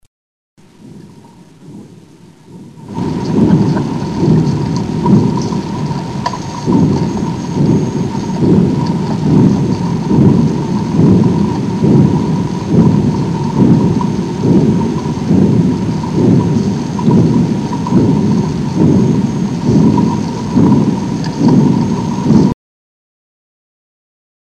Shuntgeluiden
Zacht OK
Nummer-2-Zacht-OK.mp3